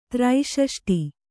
♪ trai ṣaṣṭi